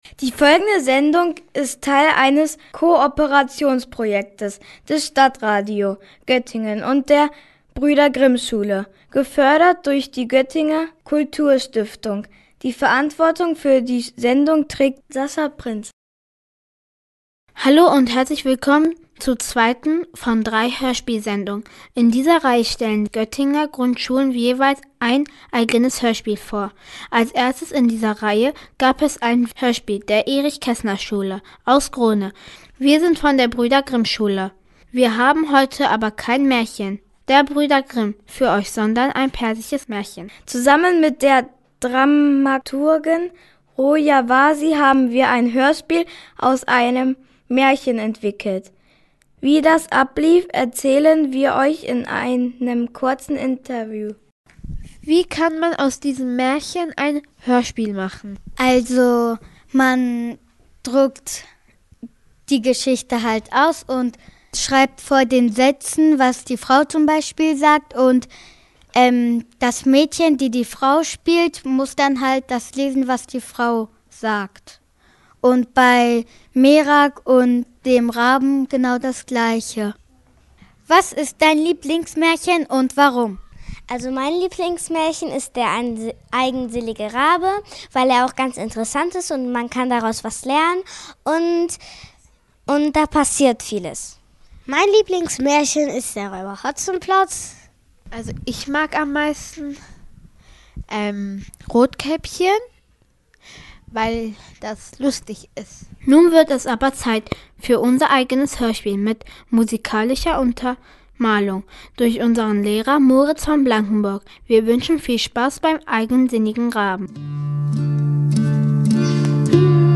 Hörspiel: Der eigensinige Rabe